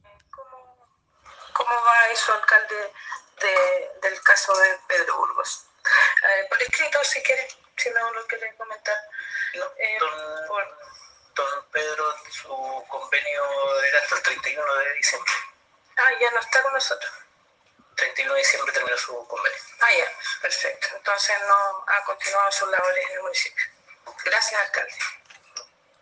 En el marco de la sesión del Concejo Municipal realizada este jueves 8 de enero, el alcalde de Los Lagos, Víctor Fritz, despejó las dudas sobre la continuidad laboral del exalcalde de Panguipulli, Pedro Burgos Vásquez, quien se desempeñaba como asesor técnico de la Unidad de Turismo bajo la modalidad de honorarios.
La reciente declaración emitida en pleno Concejo Municipal por el alcalde de la comuna de Los Lagos, Víctor Fritz, dando a conocer que el contrato de Burgos se terminó el pasado 31 de diciembre, se da en el contexto de la espera del resultado de la apelación presentada por el exjefe comunal de Panguipulli.